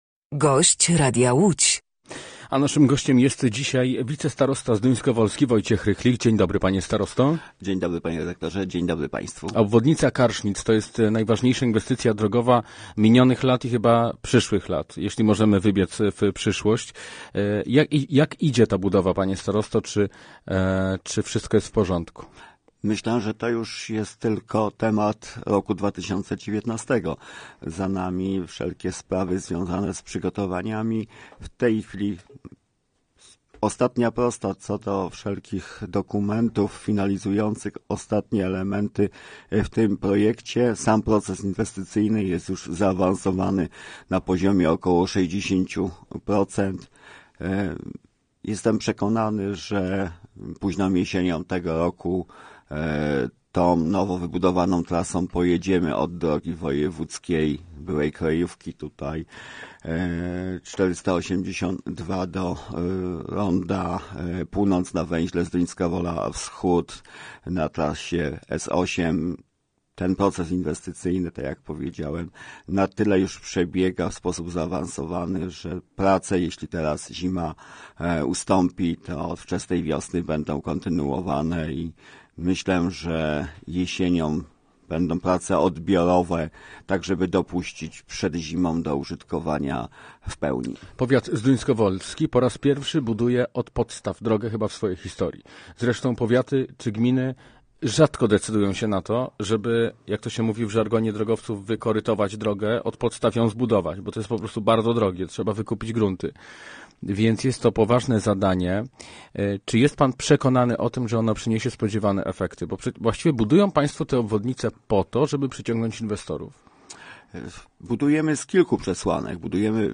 Posłuchaj całej rozmowy: Nazwa Plik Autor – brak tytułu – audio (m4a) audio (oga) Warto przeczytać Kolejny transfer Widzewa!